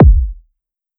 Kick (PTSD).wav